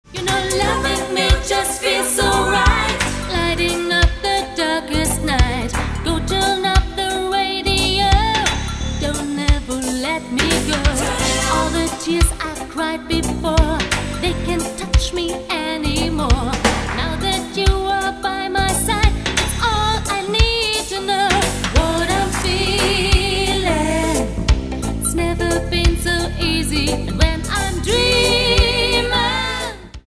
Das Pop/Rock-Musik Programm